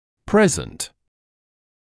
[‘preznt][‘прэзэнт]присутствующий, имеющийся в наличии; настоящее время (at present в настоящее время)